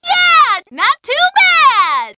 One of Daisy's voice clips in Mario Kart 7